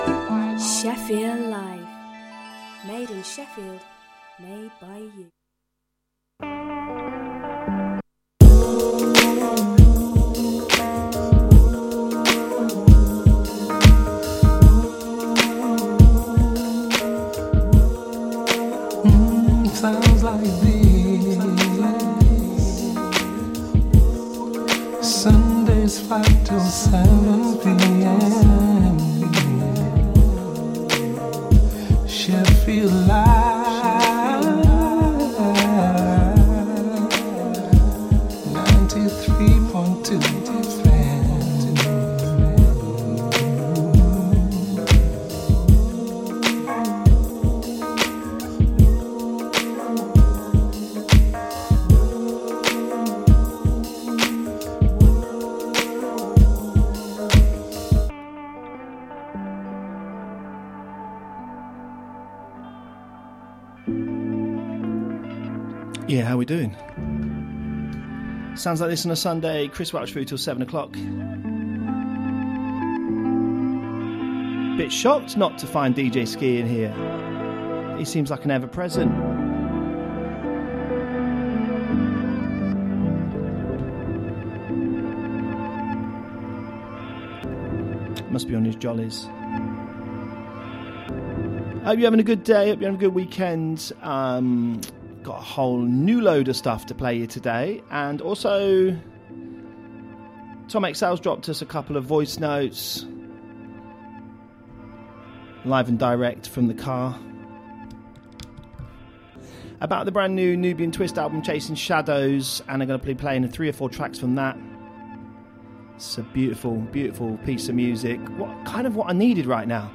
Roastin some leftfield dance type music!